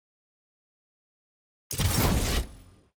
sfx-tier-wings-promotion-from-iron.ogg